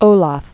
(ōläf, ōləf, läf) or O·lav II (ōläv) Known as Saint Olaf. 995?-1030.